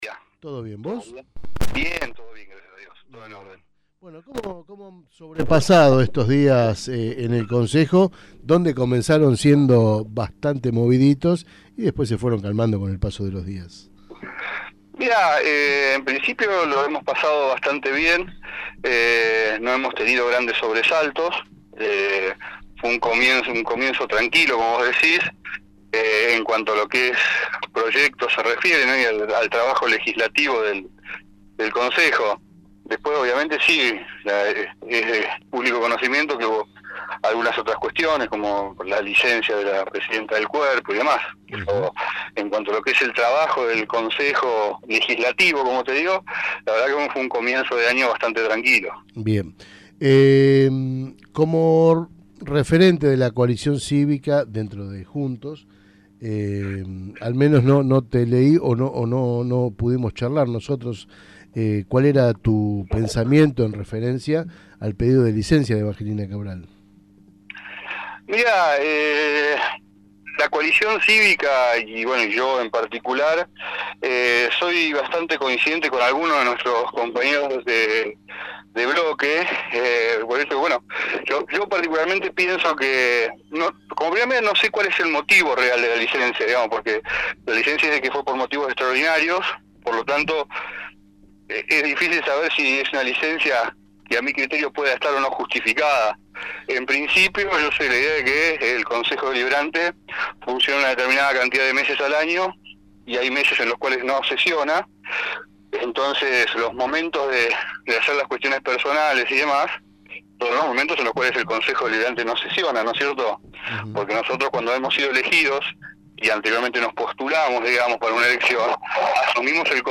Escuchá la entrevista completa a Martín Bossi: